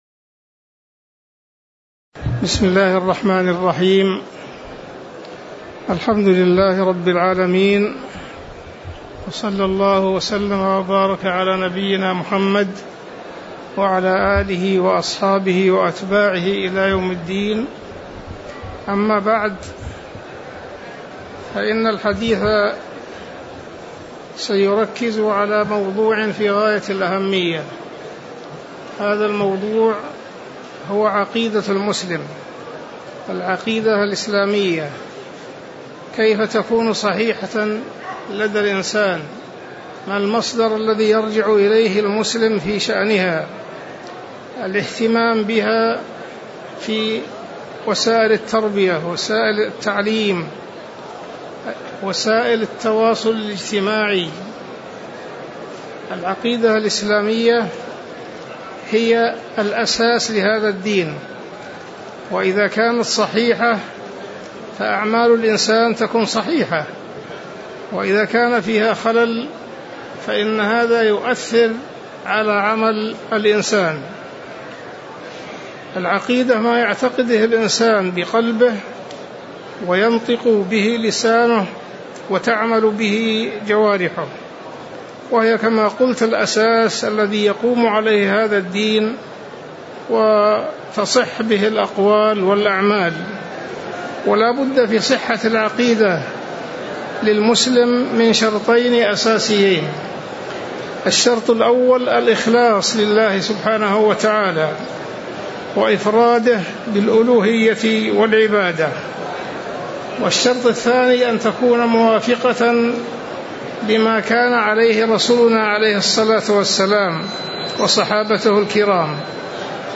تاريخ النشر ١٣ ذو القعدة ١٤٤٥ هـ المكان: المسجد النبوي الشيخ: عبدالله التركي عبدالله التركي منهج أهل السنة والجماعة في أصول العقيدة